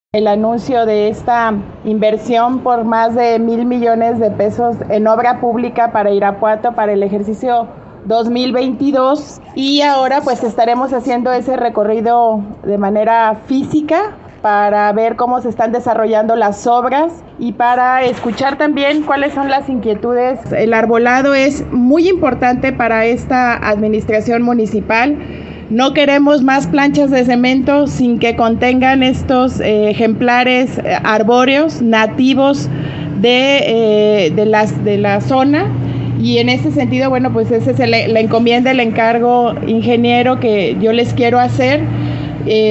AudioBoletines
Lorena Alfaro – Presidenta Municipal